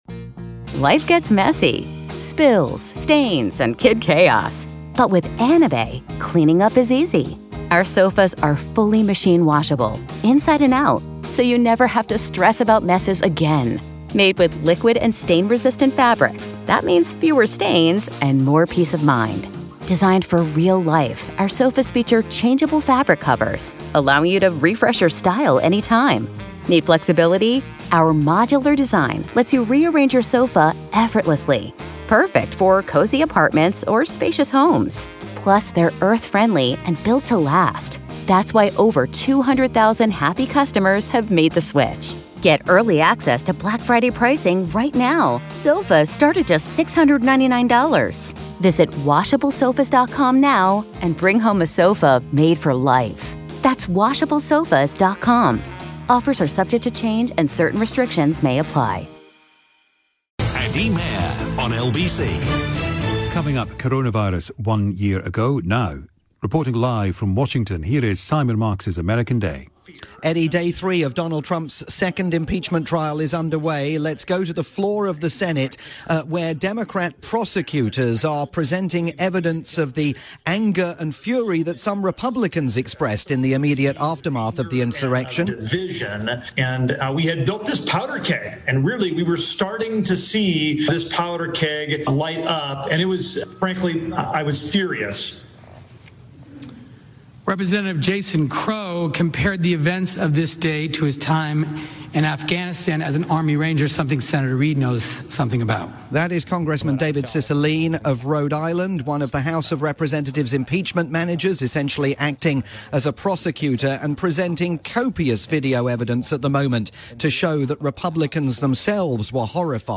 live edition of "American Day" for Eddie Mair's programme on the UK's LBC.